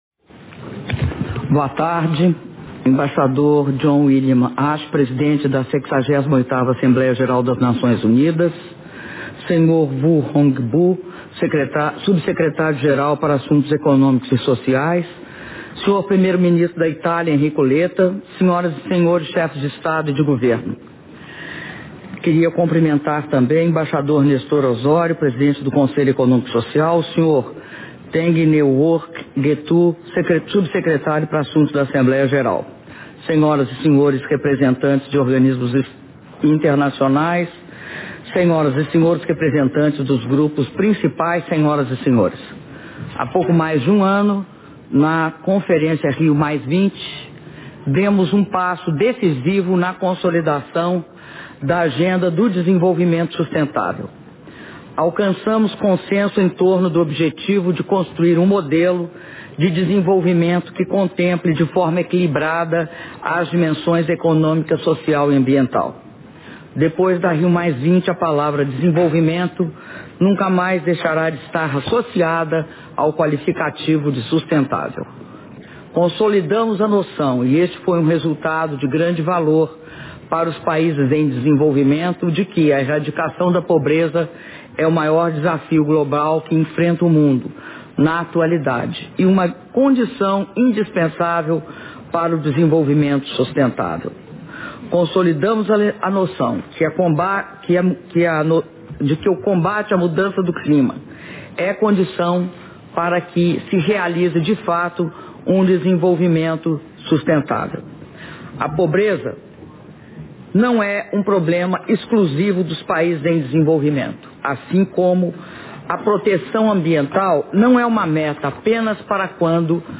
Áudio do discurso da Presidenta da República, Dilma Rousseff, durante mesa de abertura do Foro Político de Alto Nível sobre Desenvolvimento Sustentável - Nova Iorque/EUA (08min16s)